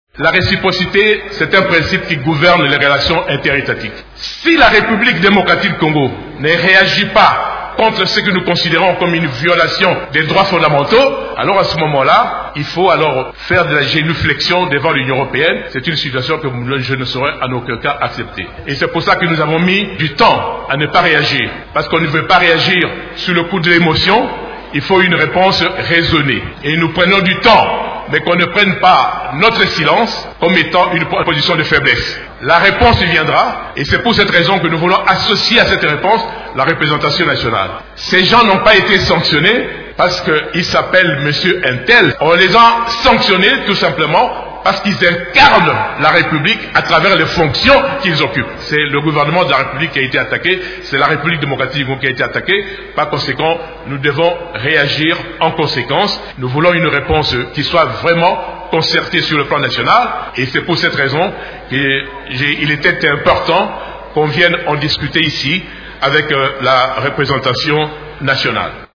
Le ministre congolais des Affaires étrangères, Léonard She Okitundu, a annoncé lundi 12 juin à l’Assemblée nationale que la RDC allait réagir aux sanctions prises par l’Union européenne contre des responsables congolais.
Vous pouvez écouter les explications de M. Okitundu dans cet extrait sonore.